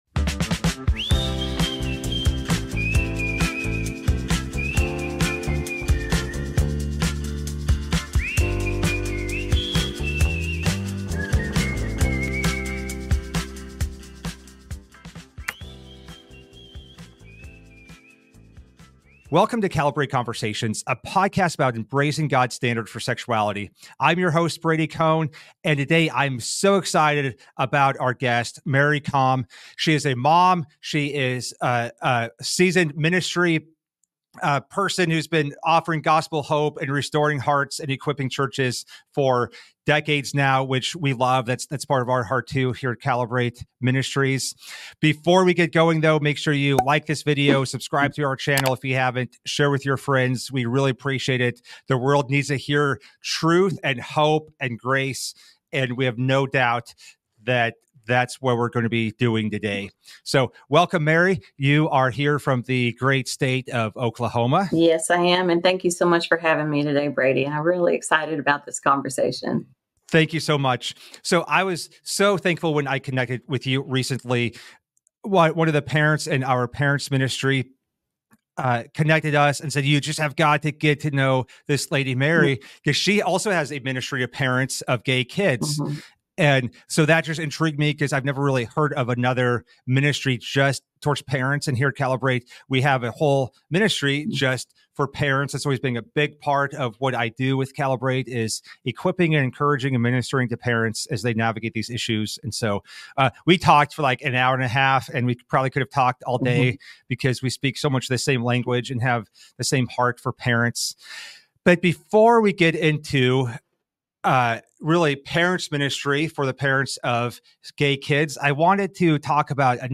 How can parents remain faithful to their beliefs while supporting children who identify as LGBTQ? Join us for a thought-provoking conversation on "Cali ...